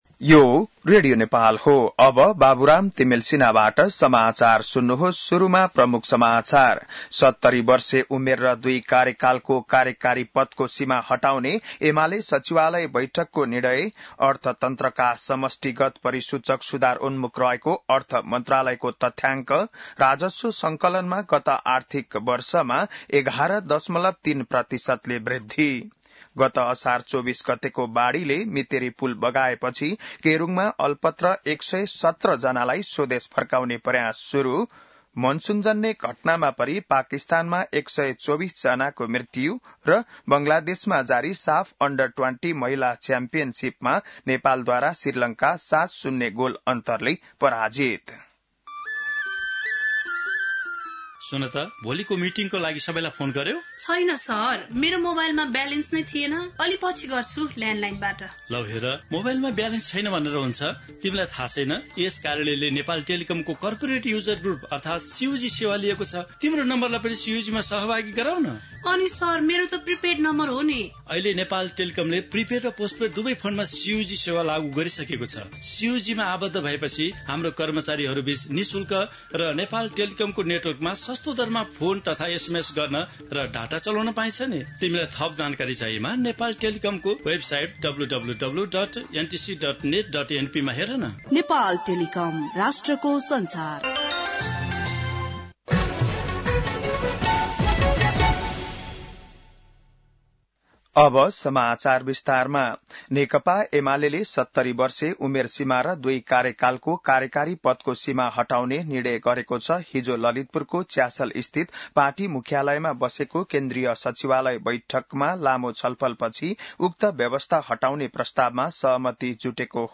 बिहान ७ बजेको नेपाली समाचार : २ साउन , २०८२